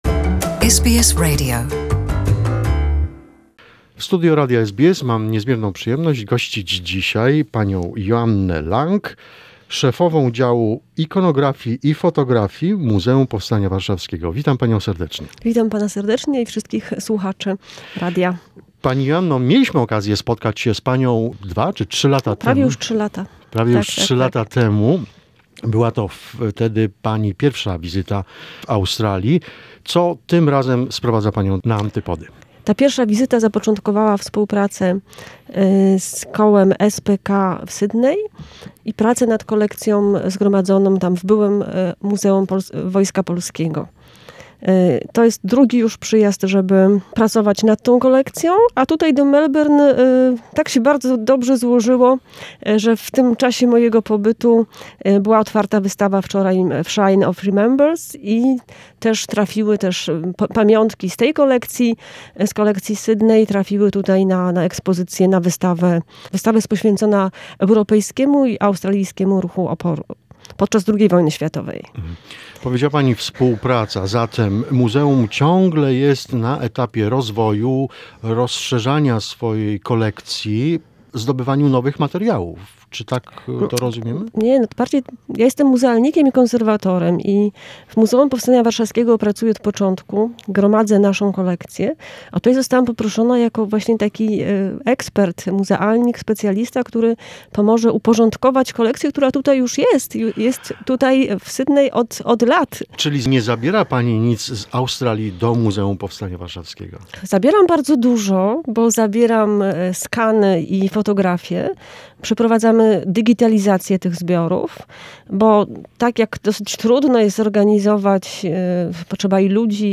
Jest to pierwsza część wywiadu.